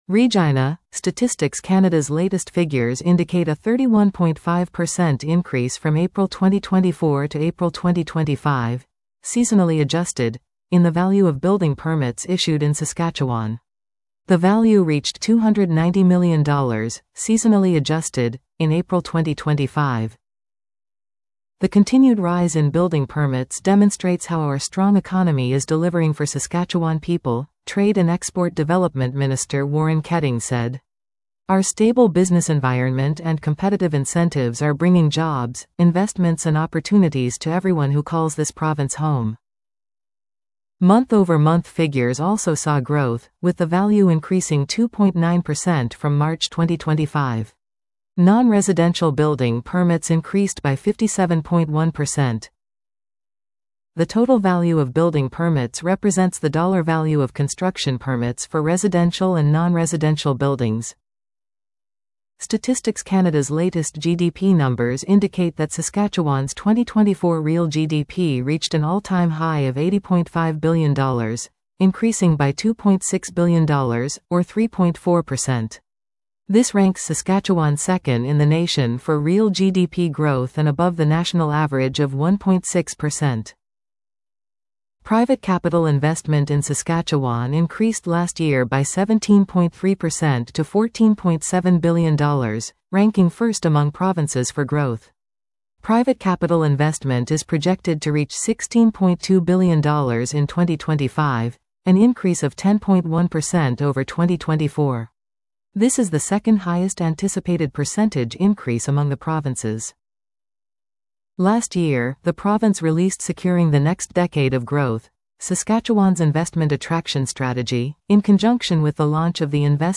AI generated by ChatGPT